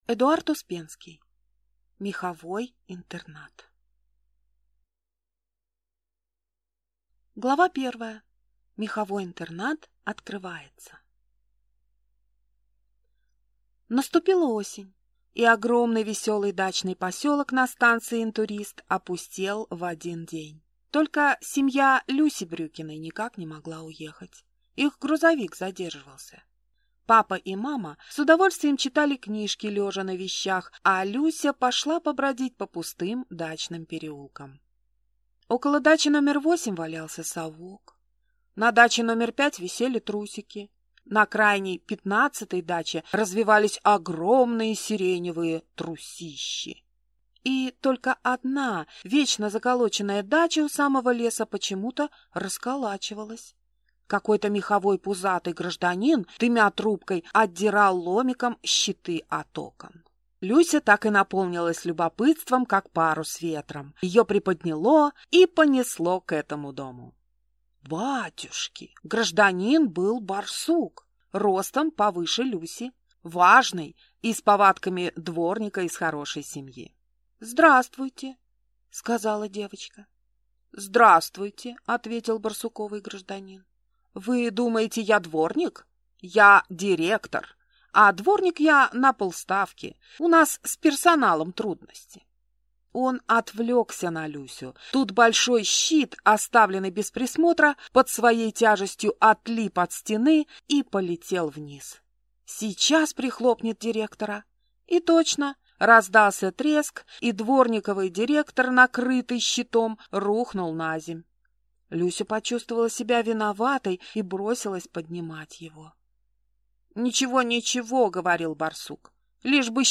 Аудиокнига Меховой интернат | Библиотека аудиокниг